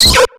Cri de Rondoudou dans Pokémon X et Y.